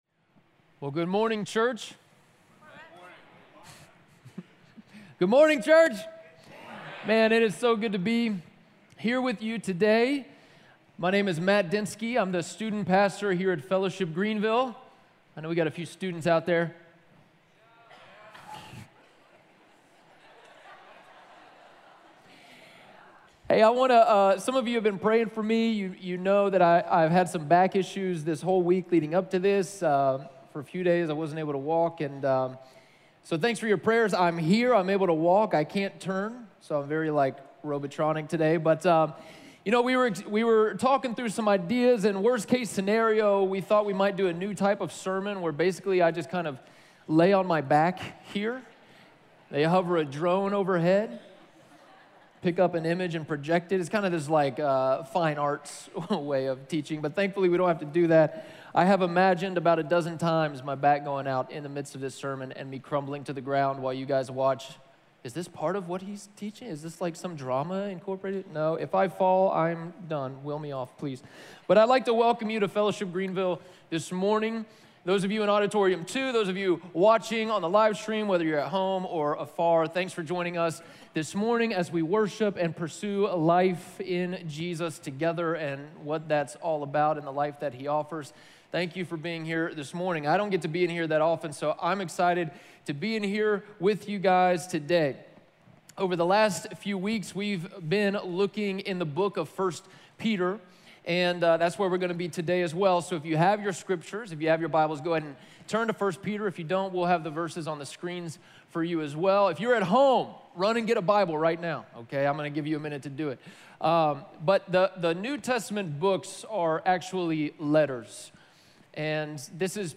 There are no sermon notes for this message. Follow along in 1 Peter 1:13-25.